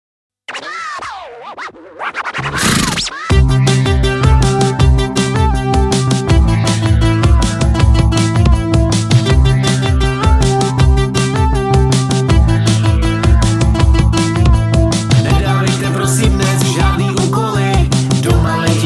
Oficiální hymna pro Český Hobby Horsing